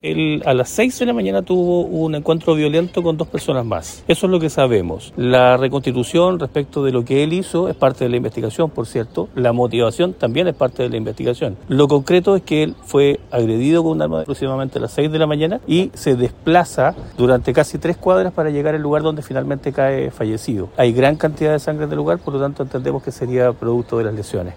El fiscal Mario Elgueta, dijo que actualmente se analiza el sitio del suceso y la participación de otras dos personas.